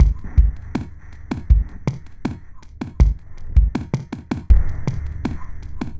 Downtempo 21.wav